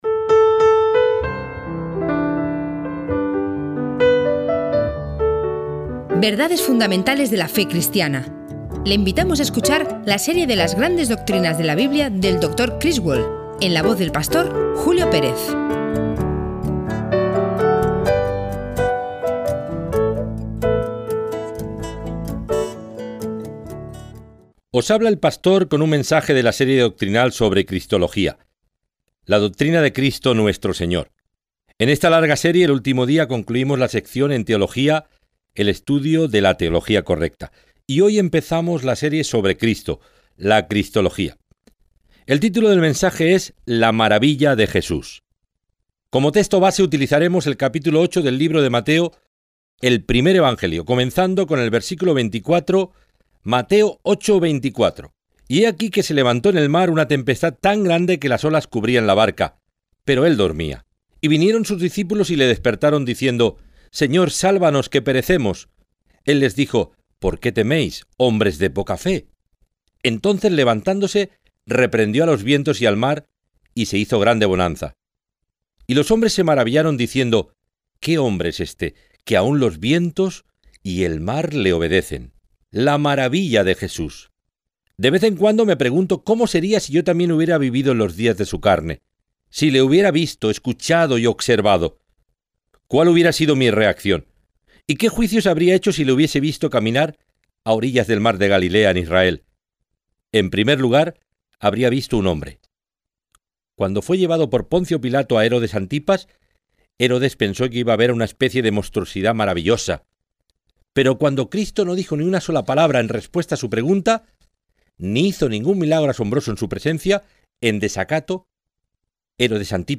W. A. Criswell Sermon Library | La Maravilla de Jesús